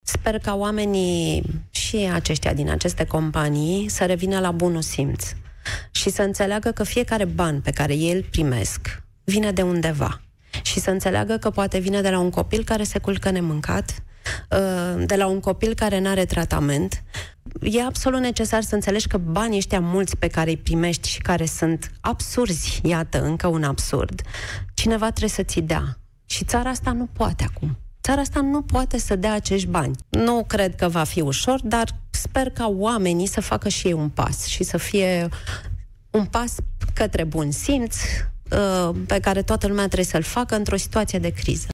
Prezentă la Piața Victoriei de la Europa FM, Gheorghiu susține că își propune să simplifice viața cetățeanului.